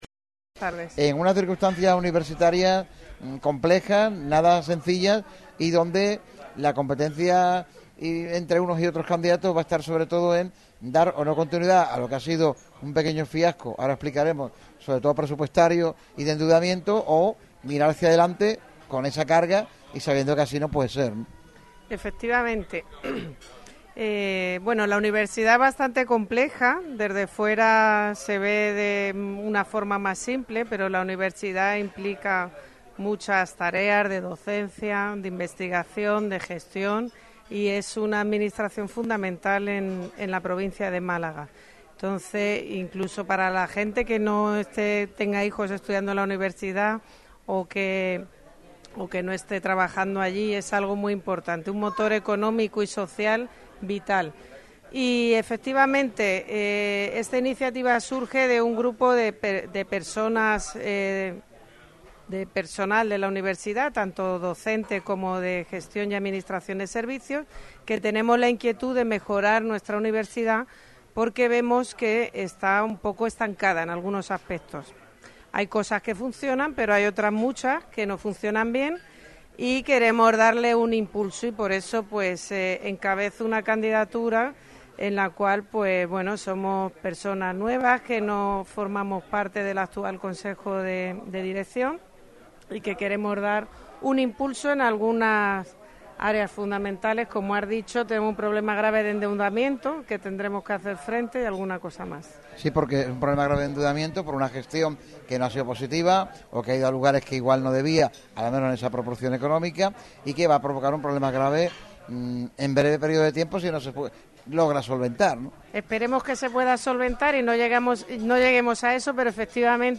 en el programa realizado en el restaurante Los Mellizos Málaga, ubicado en pleno centro de la ciudad en la calle Sánchez de Lara, 7.